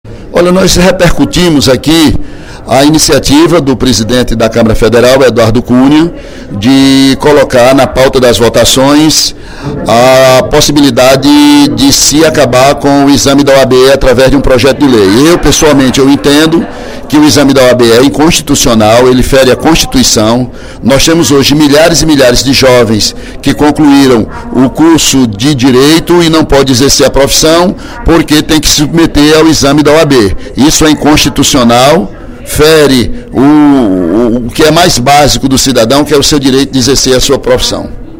Durante o primeiro expediente da sessão plenária desta terça-feira (14/04), o deputado Ely Aguiar (PSDC) criticou a realização do exame da Ordem dos Advogados do Brasil (OAB) como avaliação obrigatória para o exercício da advocacia.